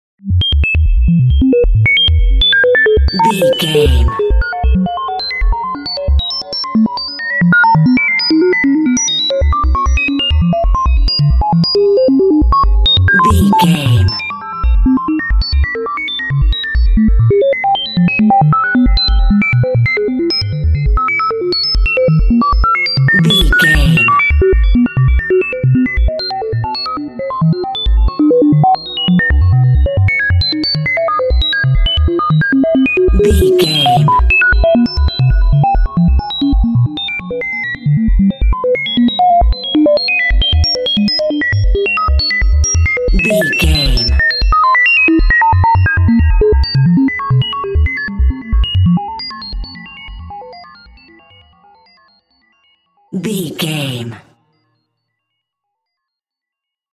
Atonal
ominous
dark
eerie
industrial
Horror synth
Horror Ambience
electronics
synthesizer